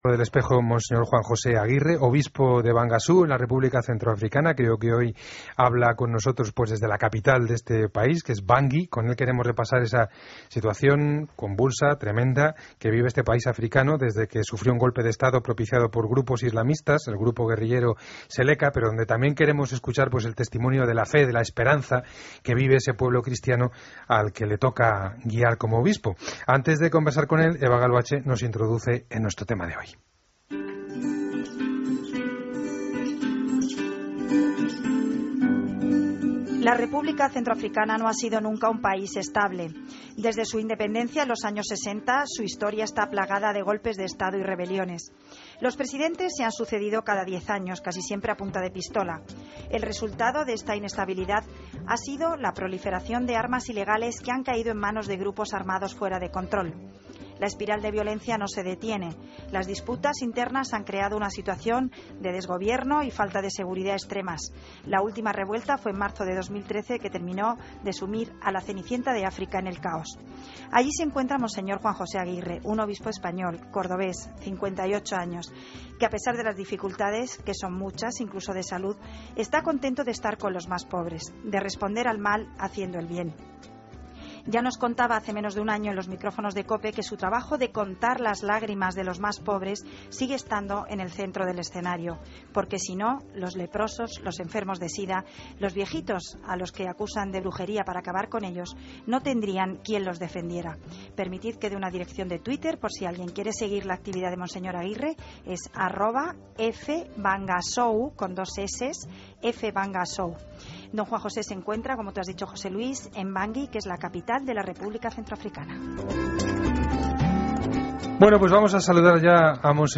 AUDIO: Escucha la entrevista completa a monseñor Aguirre en 'El Espejo' de COPE